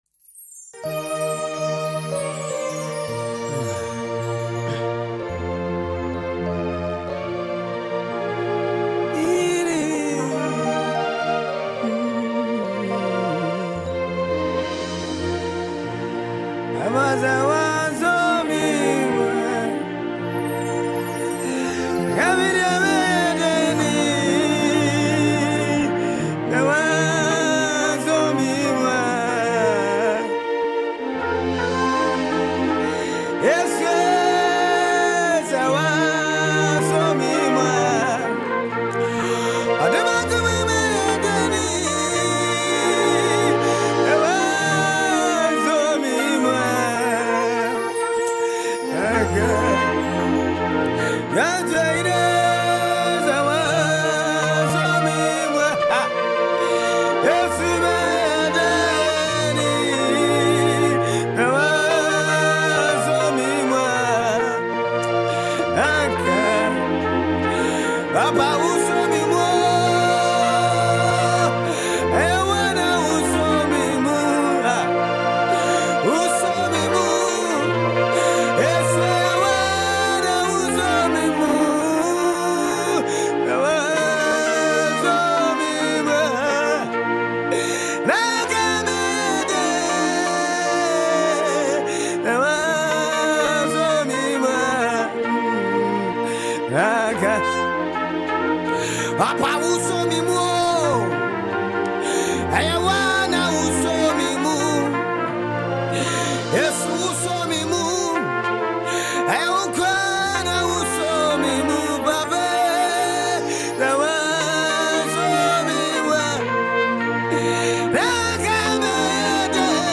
Kings Worship: Ghanaian Live Worship Songs | One King Multimedia
ghanaian-live-worship-songs